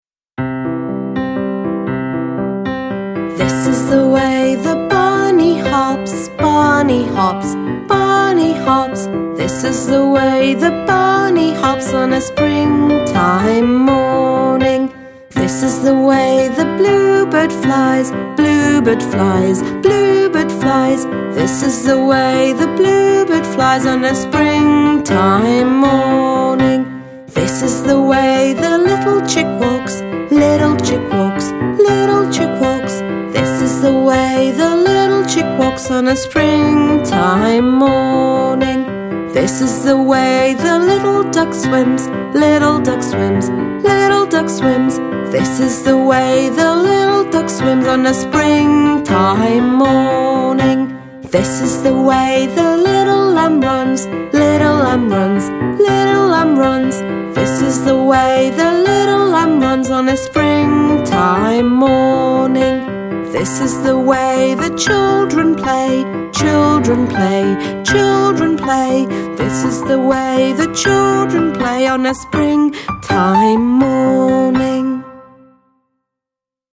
Λέμε στα παιδιά ότι θα ακούσουμε ένα τραγουδάκι για την άνοιξη. Τους βάζουμε το τραγούδι ‘Spring morning’ ( B_E05_M01 , στίχοι ) στο CD player και τραγουδώντας το ‘Spring morning κάνουμε και τις ανάλογες κινήσεις, ακολουθώντας το ρυθμό.